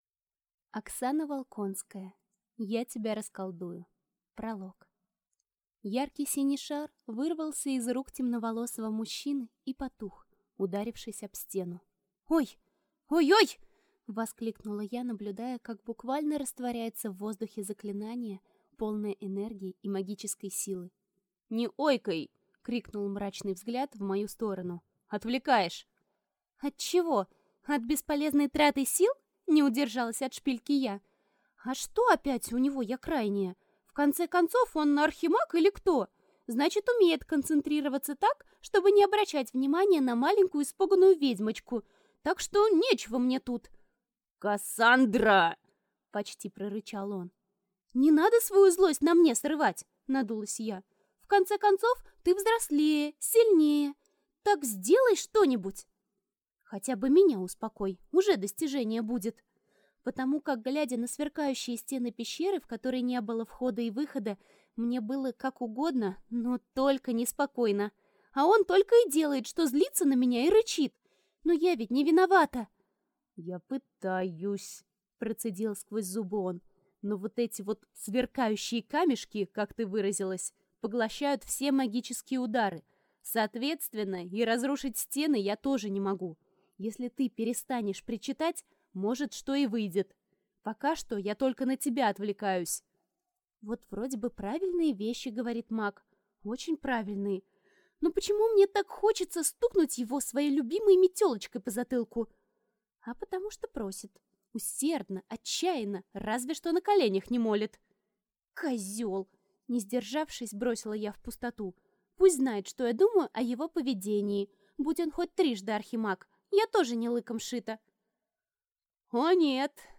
Аудиокнига Я тебя расколдую | Библиотека аудиокниг